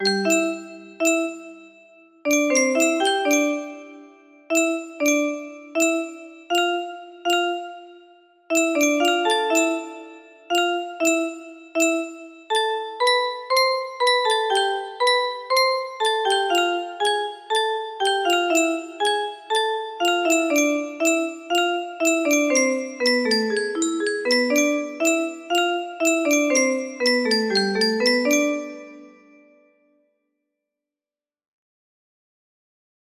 TM music box melody